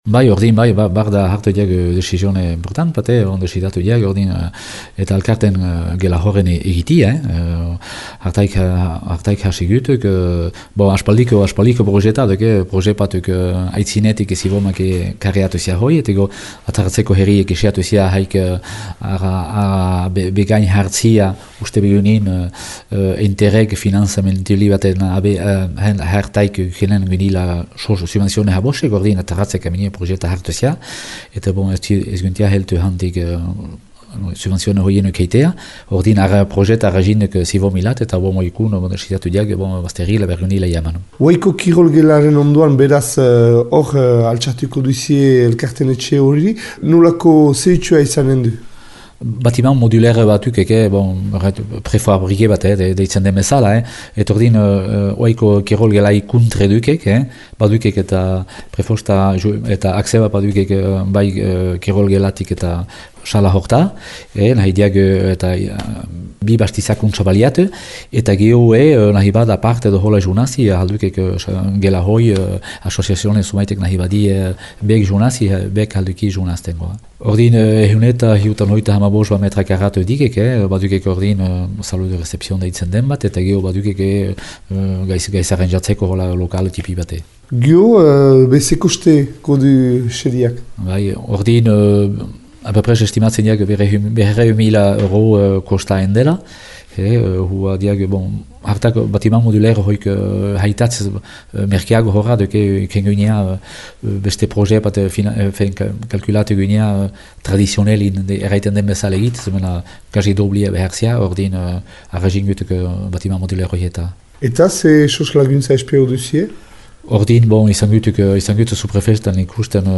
Entzün Jean-Pierre Libilbéhety, Xibero-Gaineko Sivomeko lehendakaria :